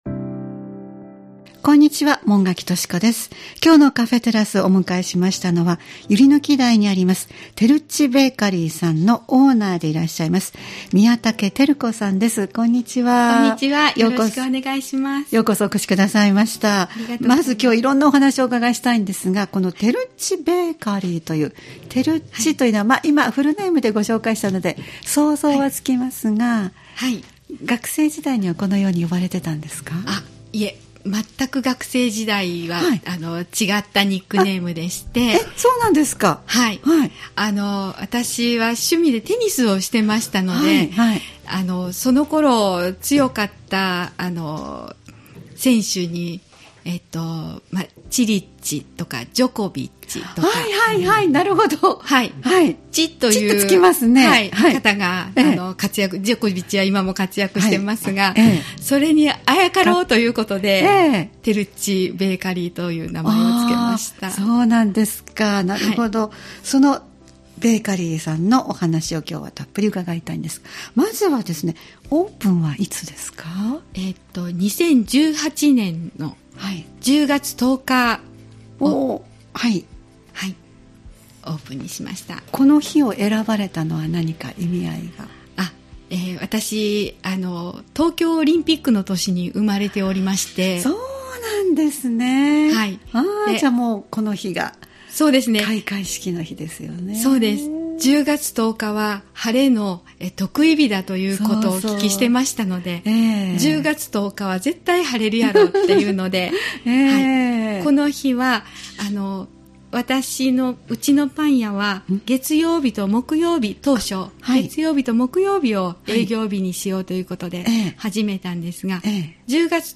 様々なジャンルで活動・活躍されている方をお迎えしてお話をお聞きするポッドキャスト番組「カフェテラス」（再生ボタン▶を押すと放送が始まります）